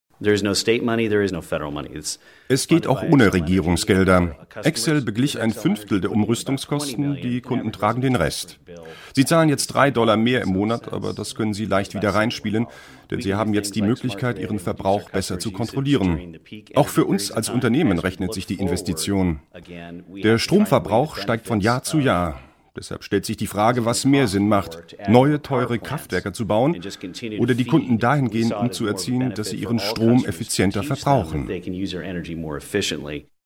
Sprachproben
Werbung - AOK Erklärfilm